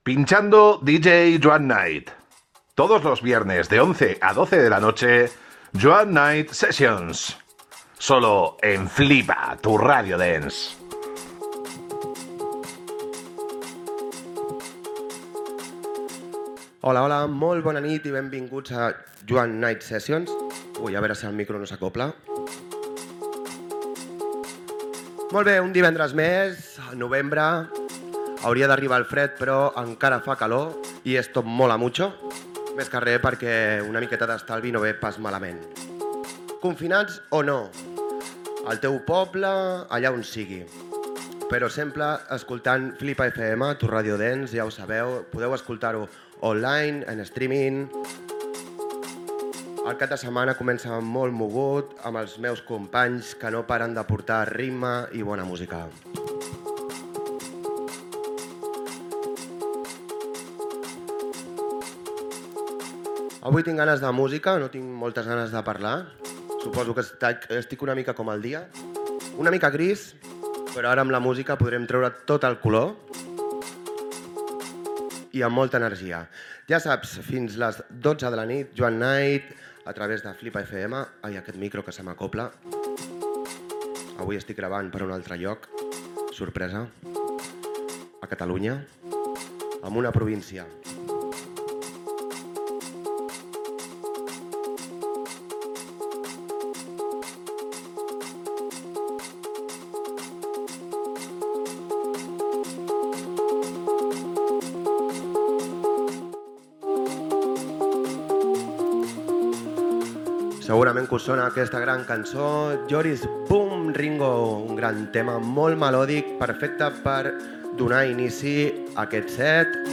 Presentació del programa
Musical